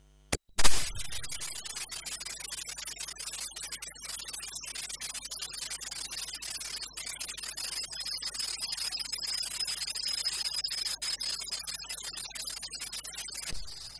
30 aug electroultrasound tone
30-aug-electroultrasound-tone.mp3